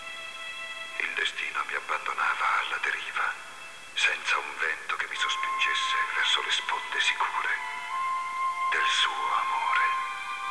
LA VOIX ITALIENNE DE VINCENT
Scène finale de l'épisode "Les Etrangers" (The Outsiders)